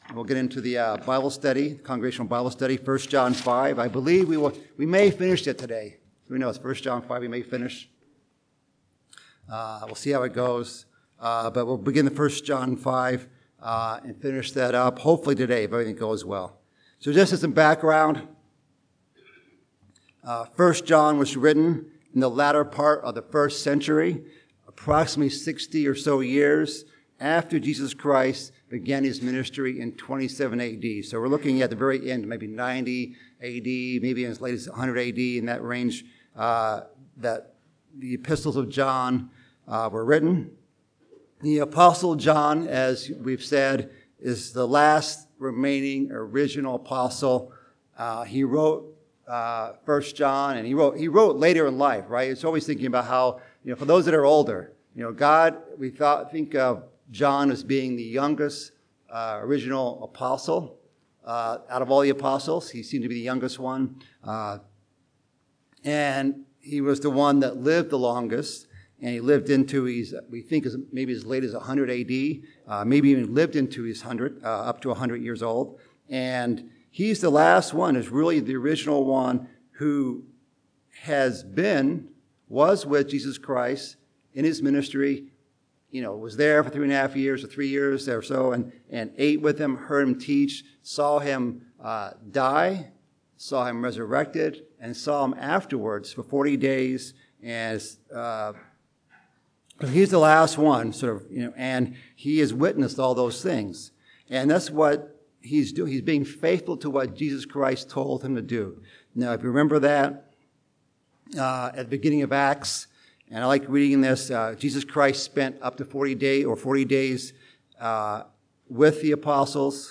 Bible Study: 1 John